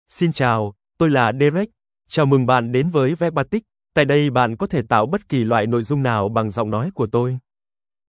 DerekMale Vietnamese AI voice
Derek is a male AI voice for Vietnamese (Vietnam).
Voice sample
Listen to Derek's male Vietnamese voice.
Derek delivers clear pronunciation with authentic Vietnam Vietnamese intonation, making your content sound professionally produced.